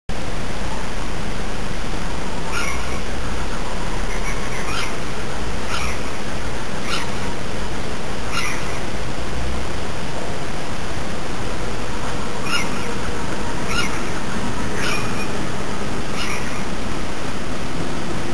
Ho fatto una piccola registrazione durante la notte...con una piccola penna..insomma l'audio è pessimo ed il volume è bassissimo.
L'uccello era molto molto vicino e richiamava un altro uccello molto più lontano, si alternavano nel canto.
le registrazioni si sento abbastanza bene, hanno però molti rumori di sottofondo che danno fastidio.
Il secondo verso è sicuramente civetta